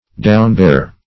Downbear \Down"bear`\, v. t. To bear down; to depress.